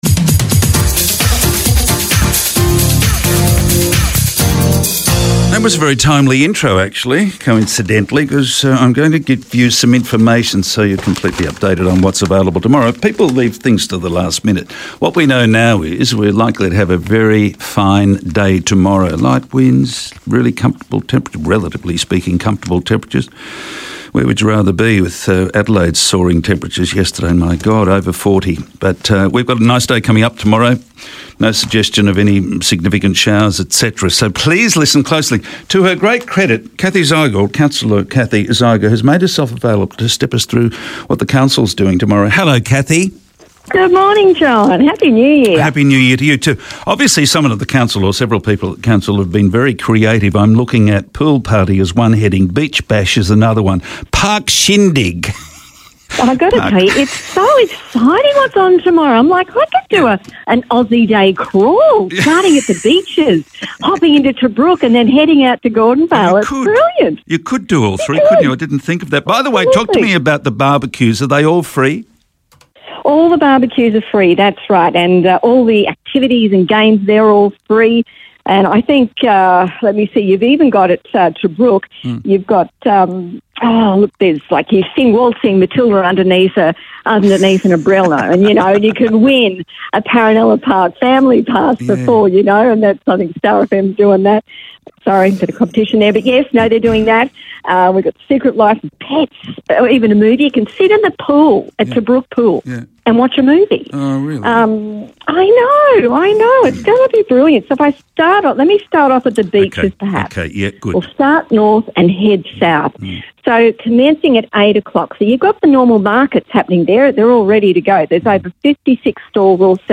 Today I spoke to Councillor Cathy Zeiger about what the Cairns Council has planned for Australia Day around the region.